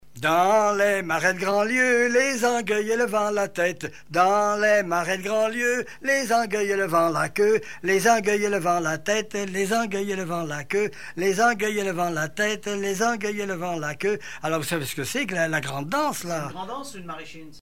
Couplets à danser
branle : courante, maraîchine
Pièce musicale inédite